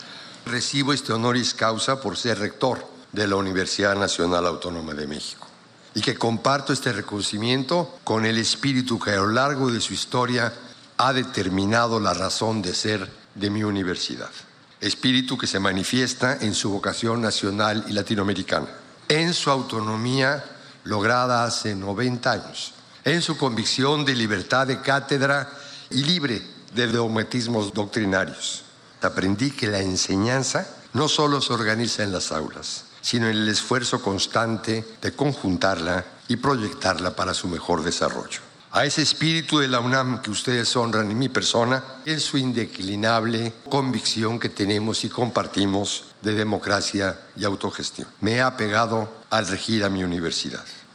La ceremonia se llevó a cabo en el teatro de la Torre Académica de la universidad sinaloense, ante rectores, autoridades educativas y estatales de los tres ámbitos de gobierno.